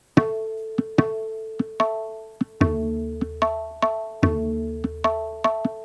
Rupak_Sample1.wav